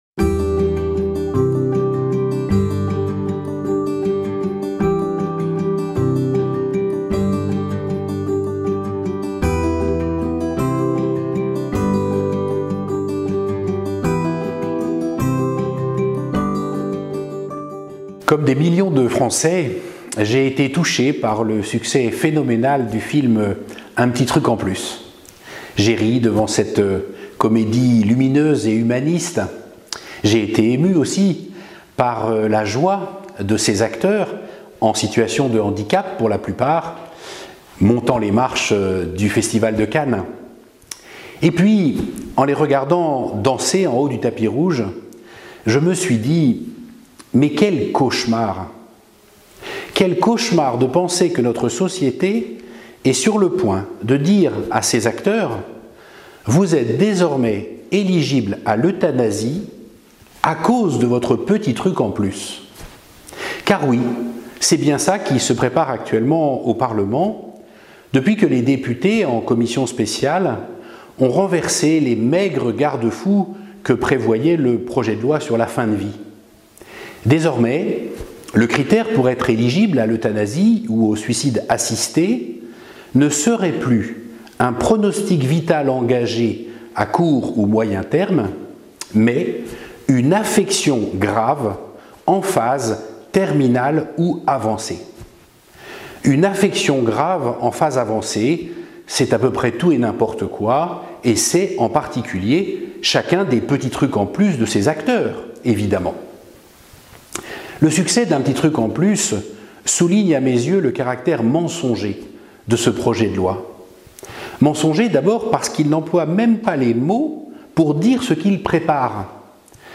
Notre Père évêque, Mgr Bruno Valentin, s'interroge et se prononce sur le projet de loi dans "Le mot de l'évêque" du mois de juin.